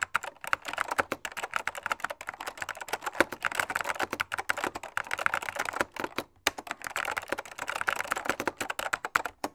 Index of /90_sSampleCDs/AKAI S6000 CD-ROM - Volume 6/Tools/KEYBOARD_TYPING
KEY TYPE 4-S.WAV